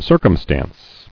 [cir·cum·stance]